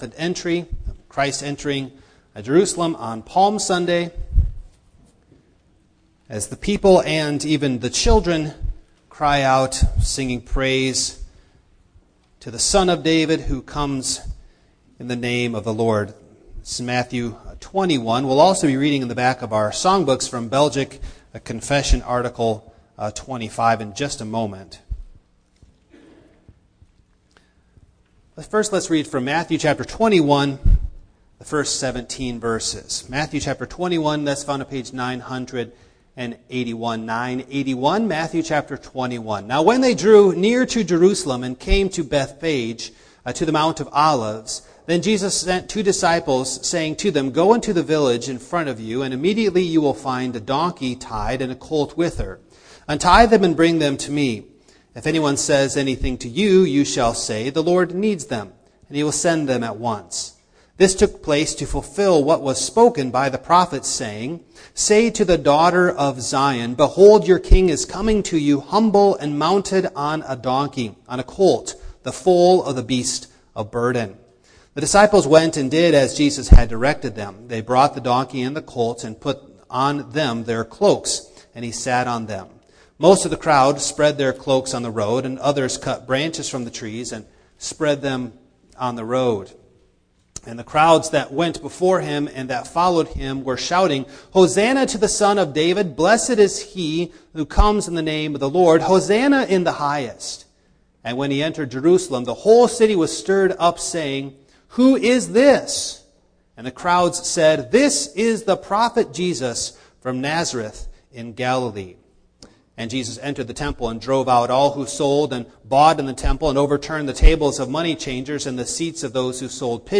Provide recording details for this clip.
Passage: Matthew 21:1-17 Service Type: Morning